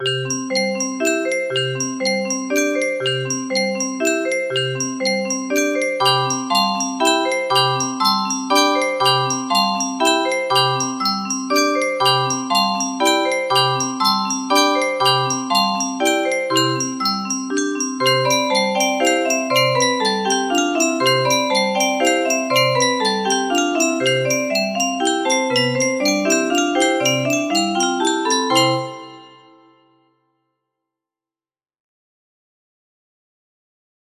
Toadstool Waltz music box melody